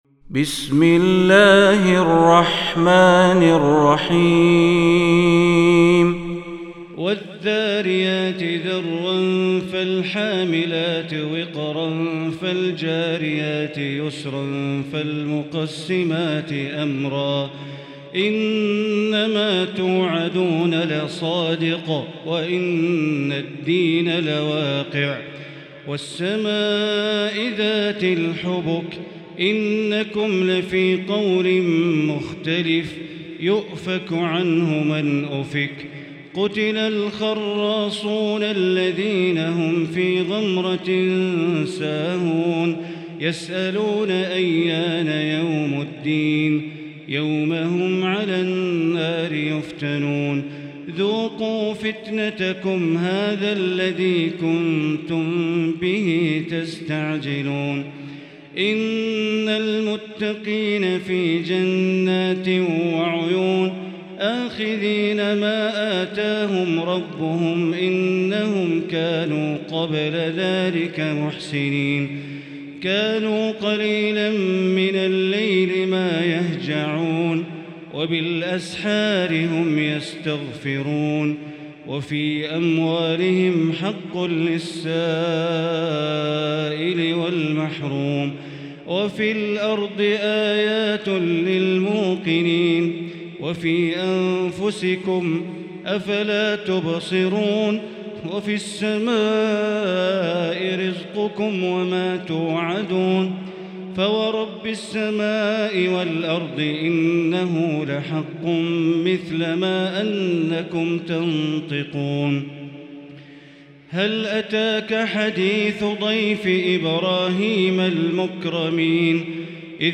المكان: المسجد الحرام الشيخ: معالي الشيخ أ.د. بندر بليلة معالي الشيخ أ.د. بندر بليلة الذاريات